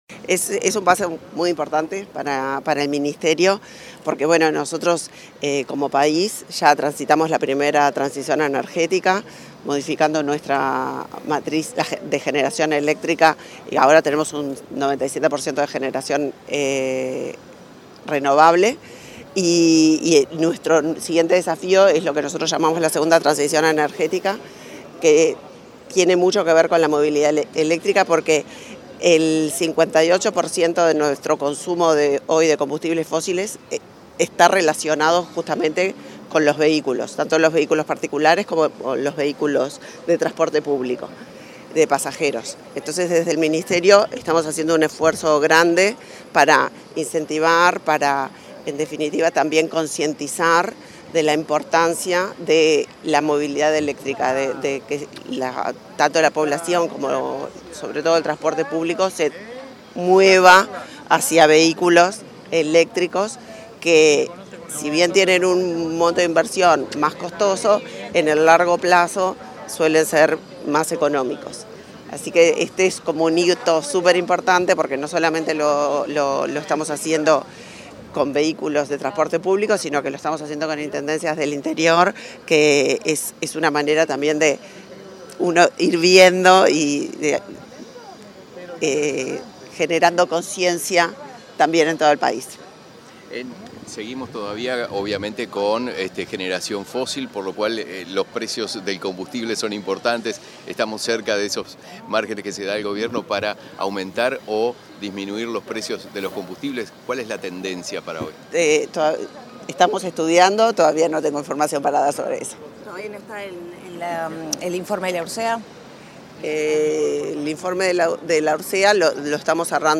Declaraciones de la ministra de Industria, Elisa Facio
Declaraciones de la ministra de Industria, Elisa Facio 28/11/2023 Compartir Facebook Twitter Copiar enlace WhatsApp LinkedIn La ministra de Industria, Elisa Facio, dialogó con la prensa, durante la entrega de cinco unidades eléctricas para las intendencias de Maldonado, Flores, Salto y Tacuarembó, en el marco del programa Subite Buses de la Dirección Nacional de Energía. El acto se realizó este martes 28 en la plaza Independencia, en Montevideo.